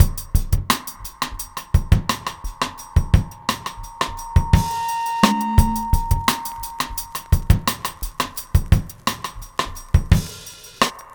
Index of /90_sSampleCDs/Best Service ProSamples vol.40 - Breakbeat 2 [AKAI] 1CD/Partition B/MEANGREEN086